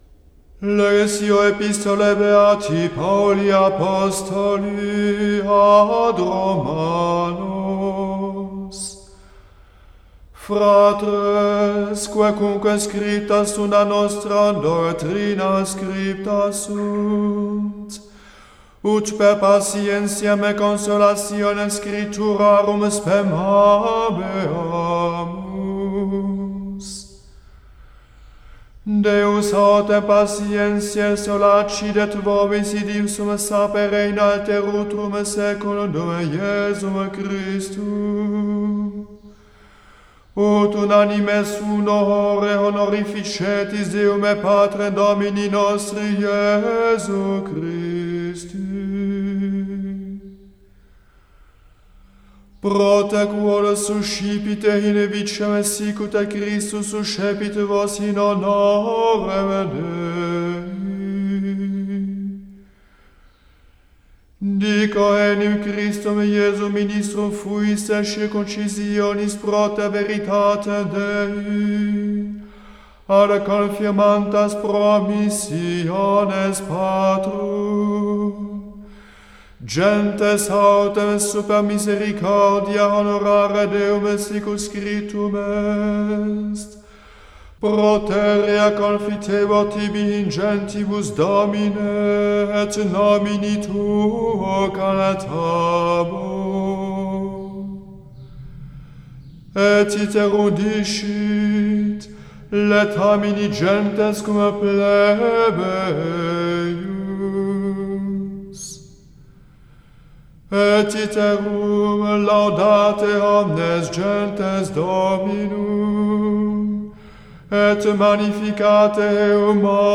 Epistola